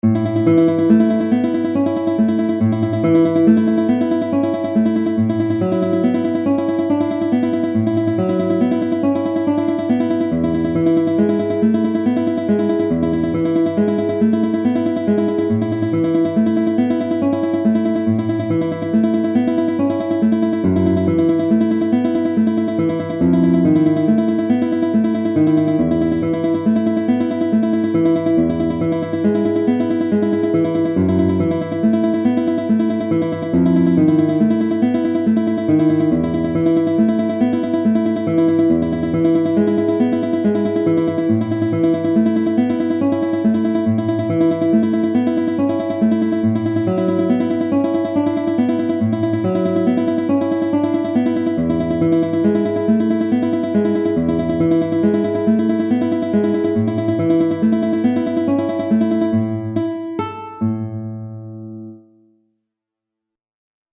Neue Musik
Sololiteratur
Gitarre (1)